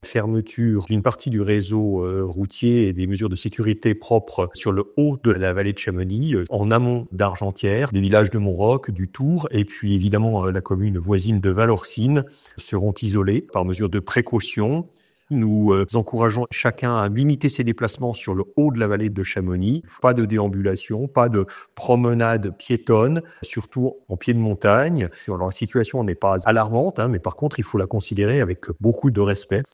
Depuis 5h du matin ce jeudi plusieurs villages sont quasiment confinés en terme de circulation comme l’explique Éric Fournier le maire de Chamonix et président de la communauté de communes.
ITC Eric Fournier 1-Risque avalanche Chamonix fermeture